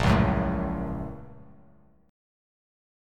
A#mM7#5 chord